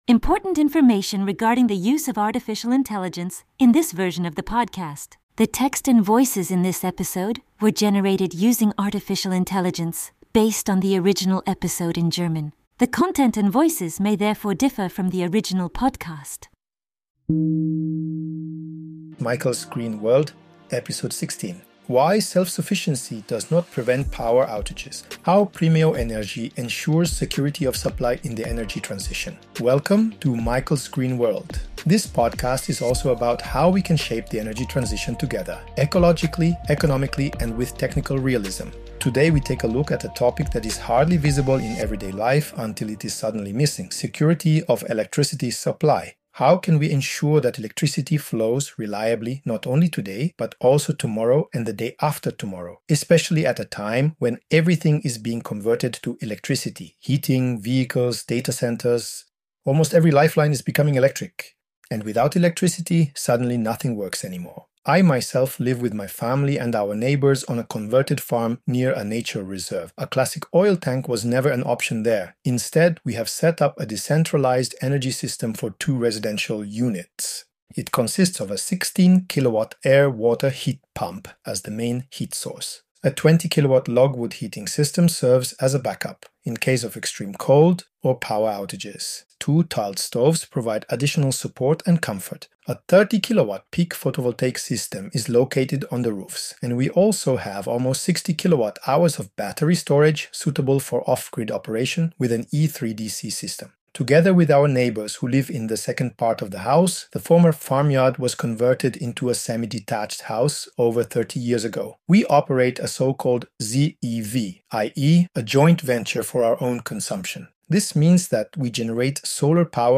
(AI Voices)